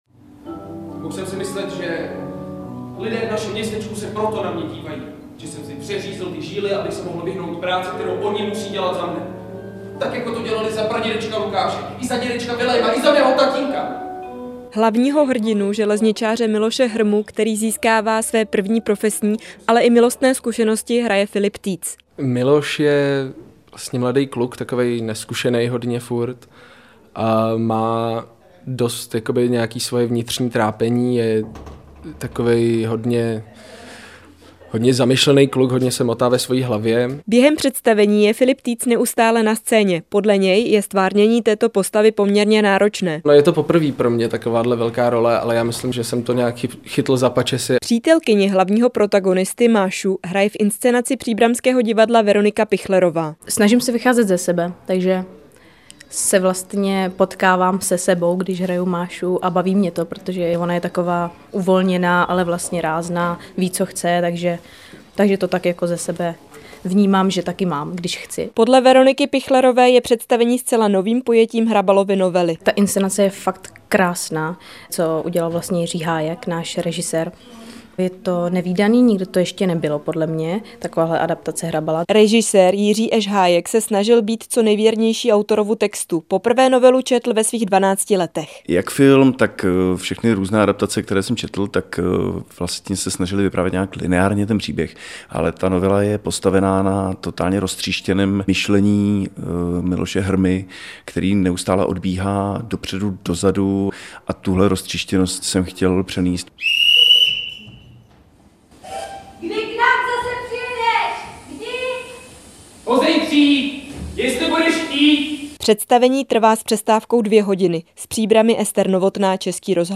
Zprávy Českého rozhlasu Střední Čechy: Novou sezonu zahájilo příbramské divadlo adaptací novely Bohumila Hrabala Ostře sledované vlaky - 21.02.2025